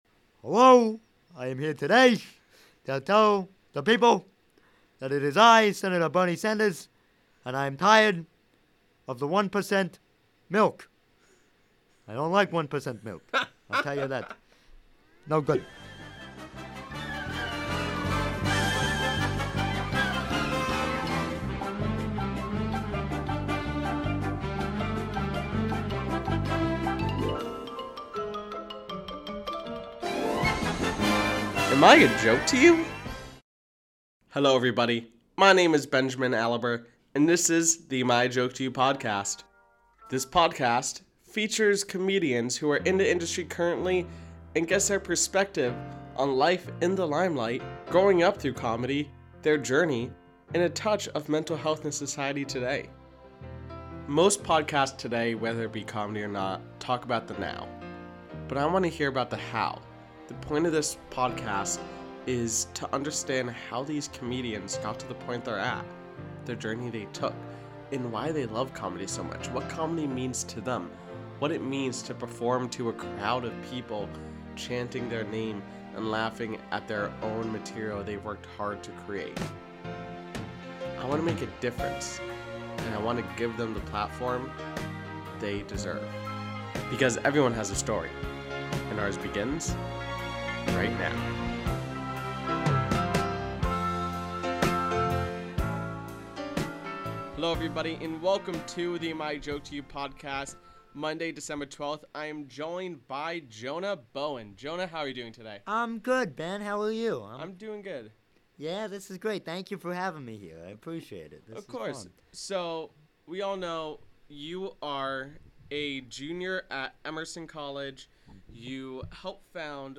interviews comedians and gets to know their upbringing into comedy and what makes it special for them.